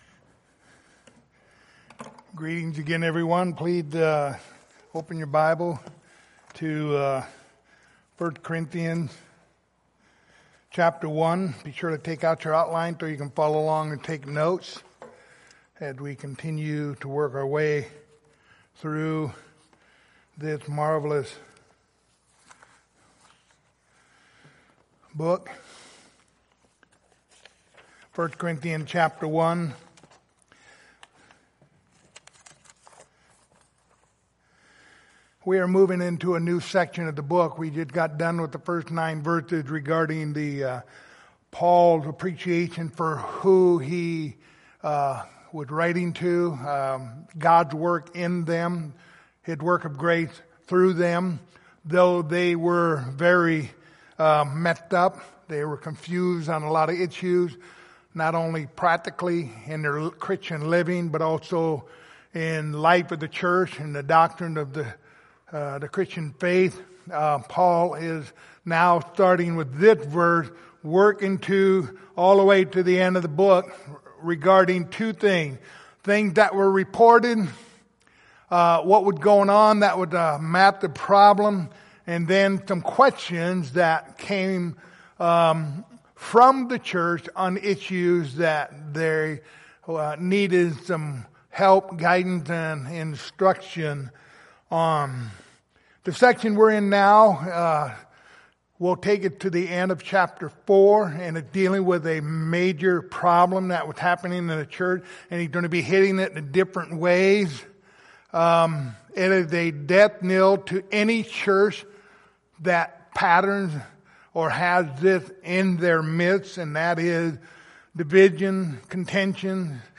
1 Corinthians Passage: 1 Corinthians 1:10-13 Service Type: Sunday Morning Topics